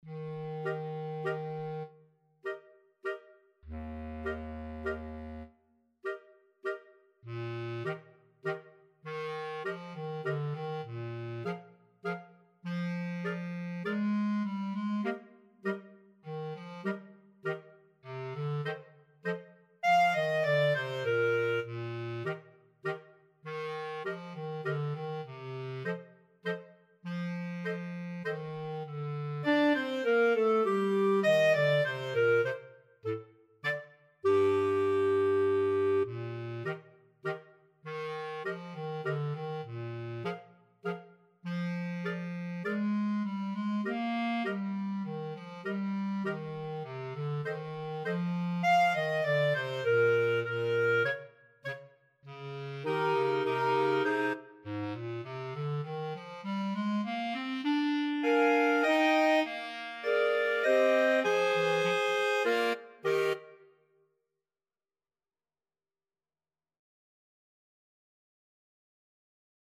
Clarinet 1Clarinet 2Clarinet 3Clarinet 4/Bass Clarinet
3/4 (View more 3/4 Music)
Slowly = c.100
Clarinet Quartet  (View more Easy Clarinet Quartet Music)